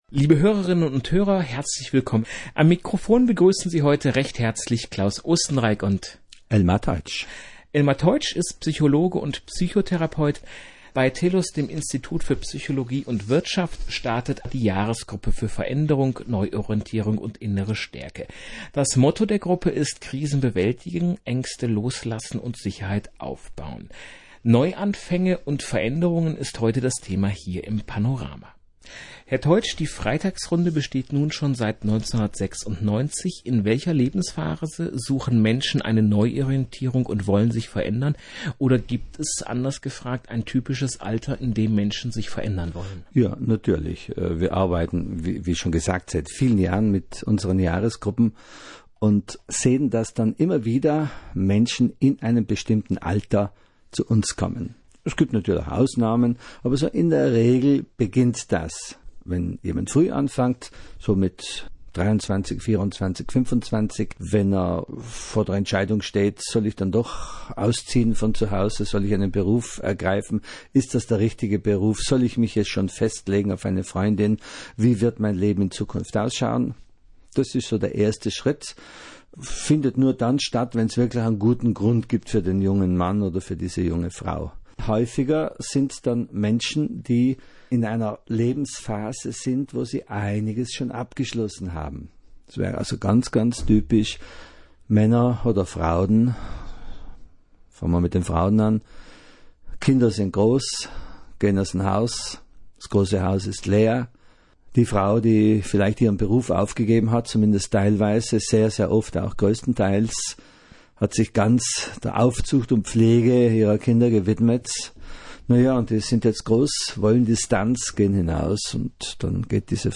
Dies ist ein Service aus unserer Reihe „Radio im WEB“ – Wer also die Sendung versäumt hat, oder sie nochmals hören möchte, kann jetzt hier Ausschnitte hören: